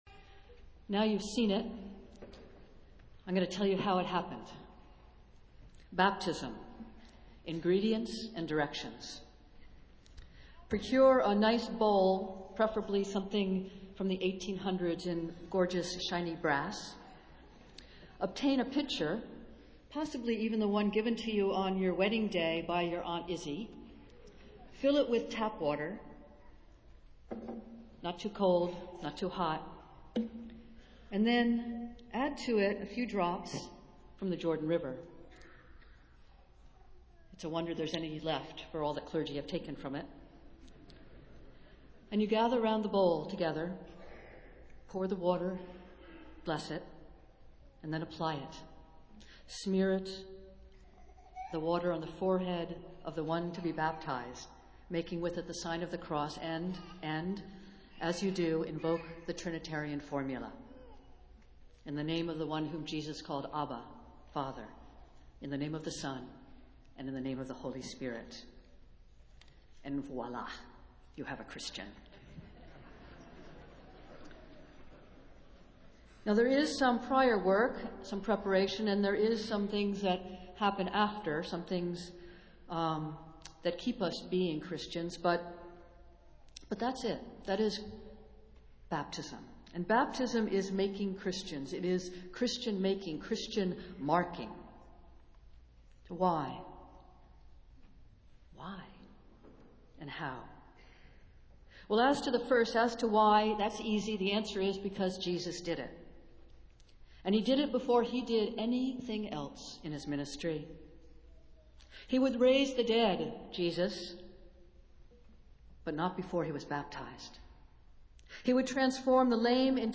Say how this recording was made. Festival Worship - Baptism of Jesus Sunday